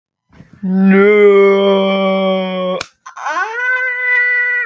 Sound Effects
Noooo Slap Ahh